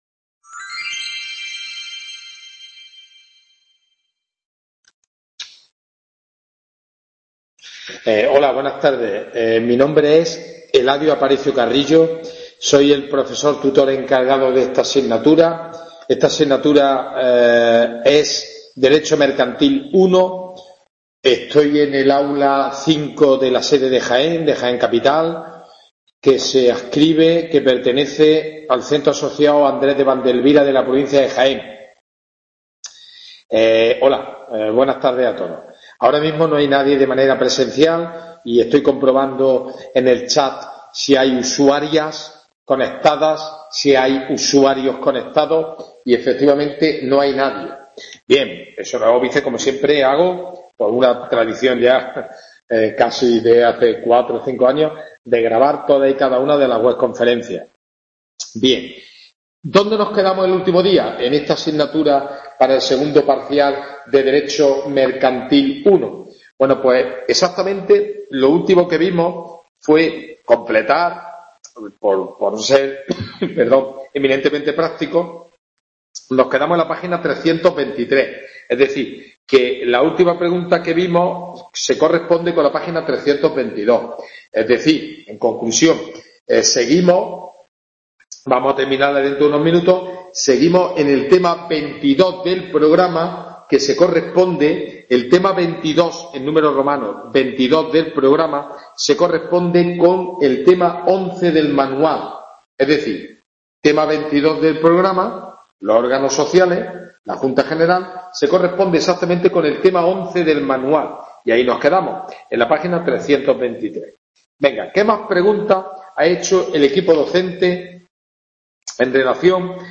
WEB CONFERENCIA "Dº MERCANTIL I"